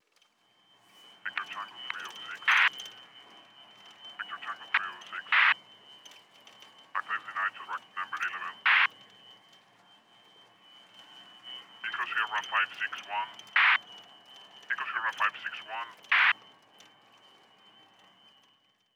Instrucciones del capitán desde una cabina de un avión Boeing 747
Sonidos: Transportes